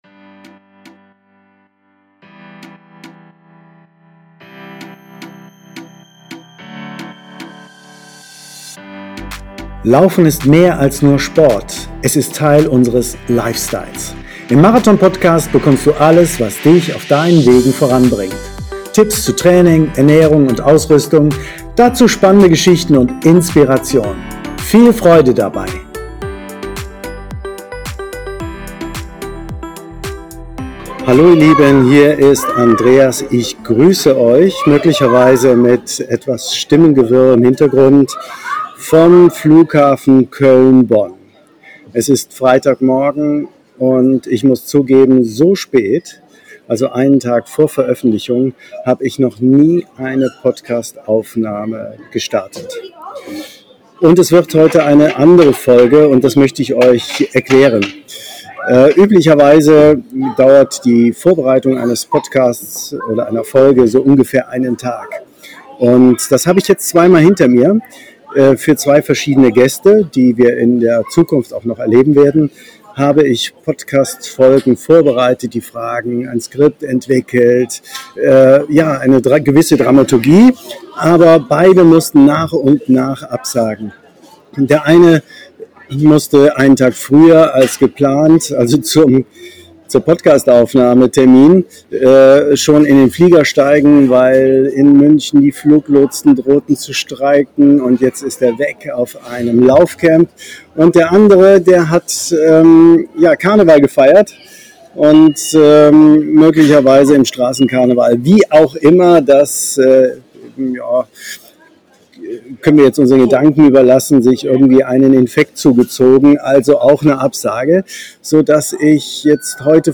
Grüße vom Flughafen Köln-Bonn ~ MARATHON PODCAST Podcast
Folge 85: Wünsche zum Geburtstag Ein ungewohnter Ort für eine Podcast-Folge: der Flughafen Köln/Bonn!